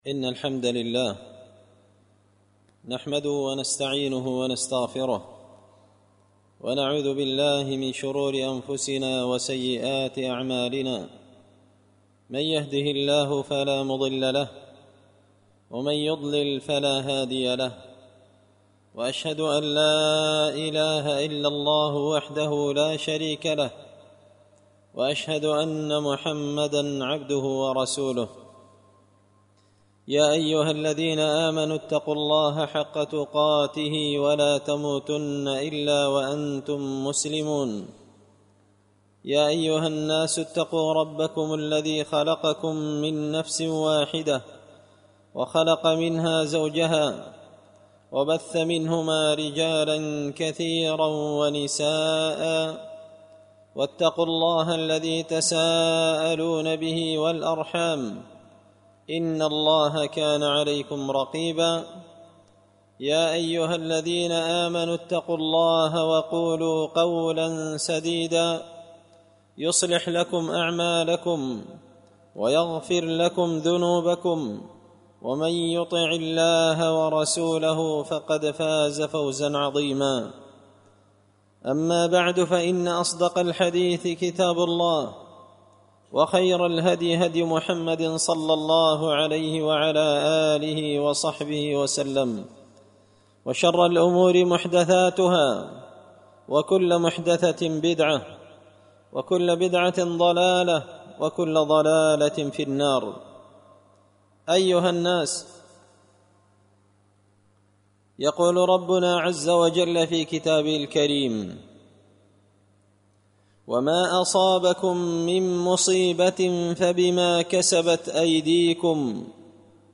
خطبة جمعة بعنوان – من الاسباب التي تفرج بها الشدائد
دار الحديث بمسجد الفرقان ـ قشن ـ المهرة ـ اليمن